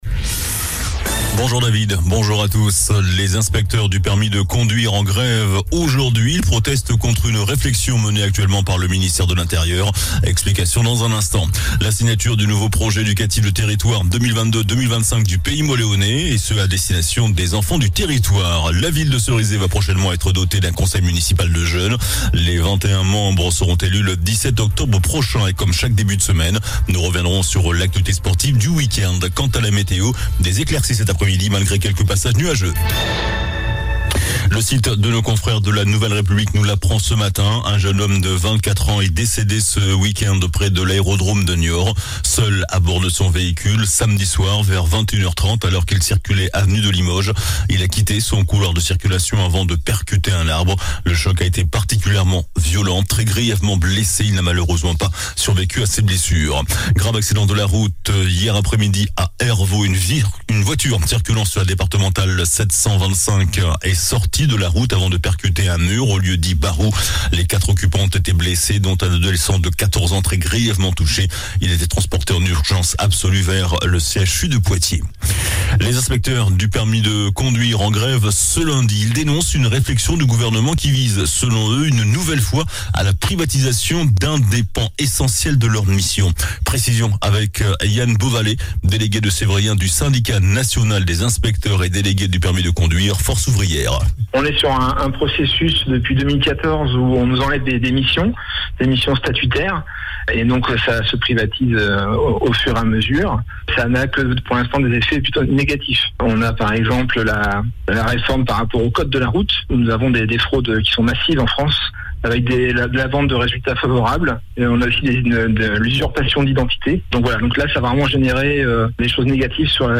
JOURNAL DU LUNDI 03 OCTOBRE ( MIDI )